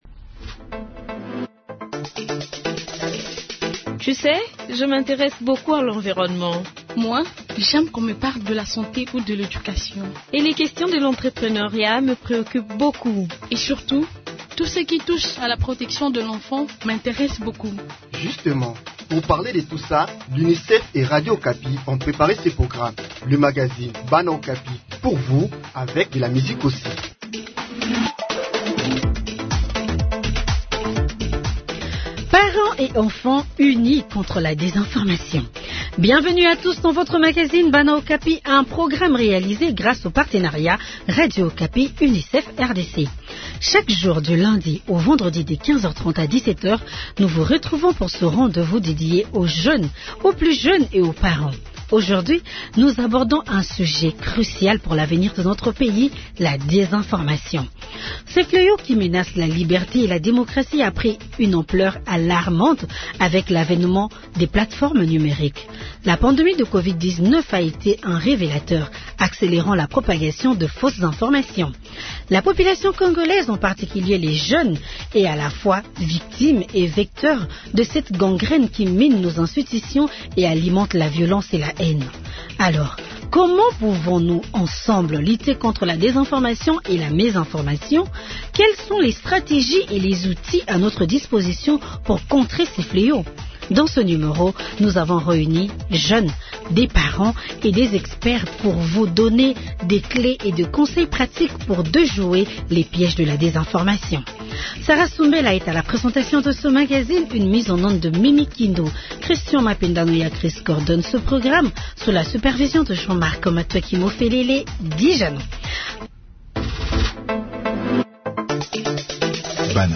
Dans ce numéro, nous avons réuni des jeunes, des parents et des experts pour vous donner des clés et des conseils pratiques pour déjouer les pièges de la désinformation.